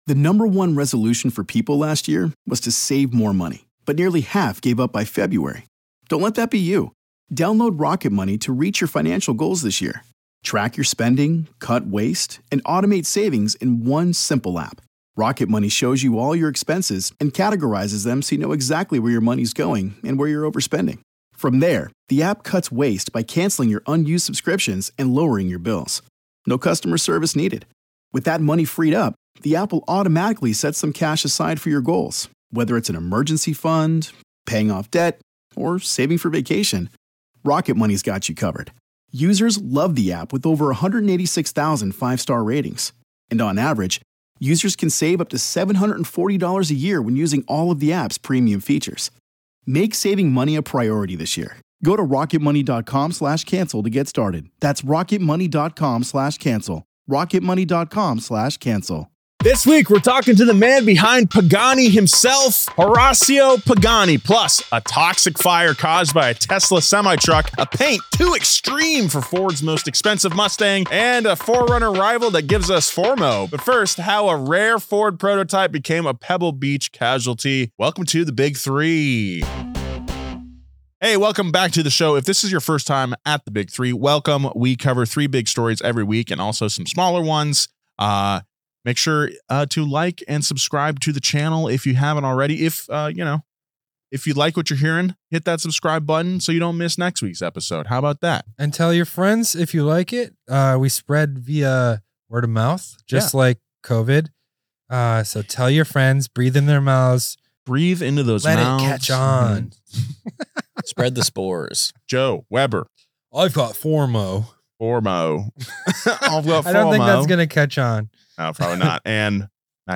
The Big Three #25: Horacio Pagani Interview
This week the guys chat about a trailer fire at Pebble Beach that destroyed a rare concept car, a Tesla blaze that lasted days, and a diesel Ford SUV that the US won’t get. Plus, an exclusive interview with the man behind Pagani Hypercars: the one and only Horacio Pagani.